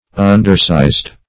Undersized \Un"der*sized`\, a.